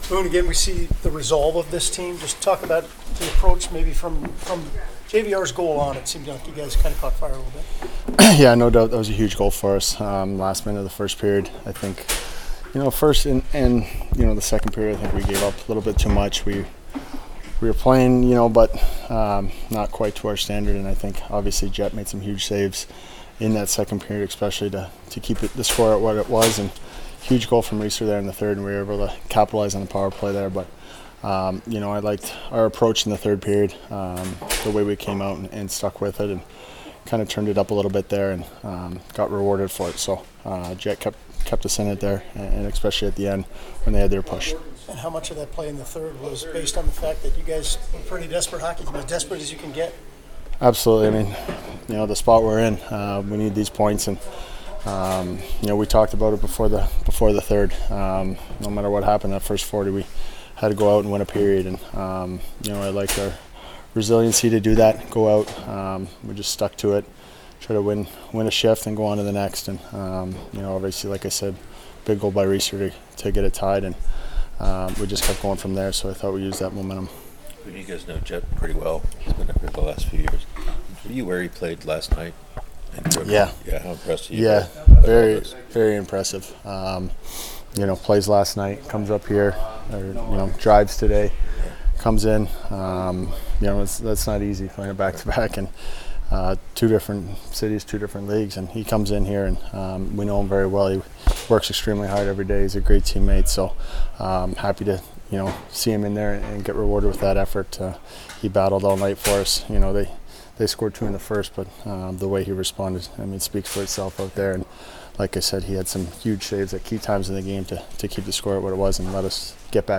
Blue Jackets come back to beat Sabres, 3-2, keeping Playoff push alive; Captain Boone Jenner speaks to Media about team playing hard